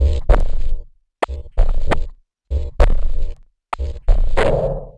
Abstract Rhythm 11.wav